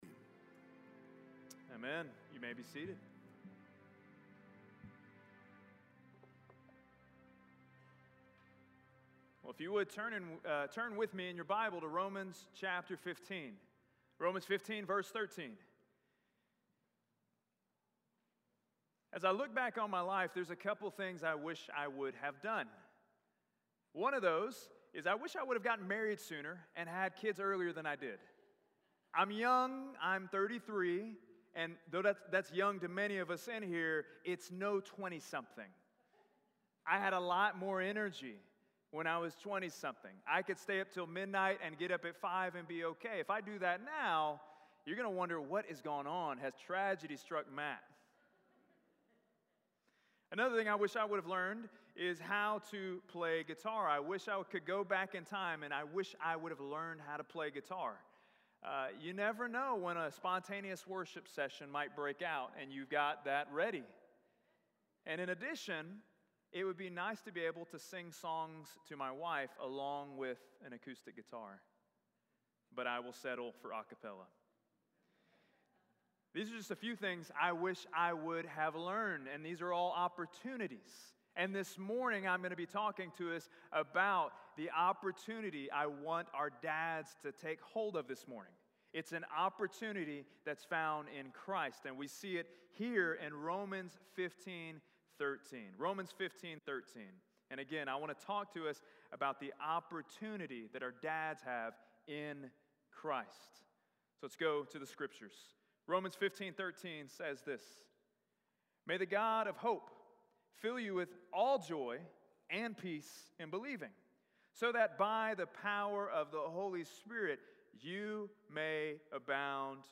Opportunity (A Father's Day Message) (6/18/23)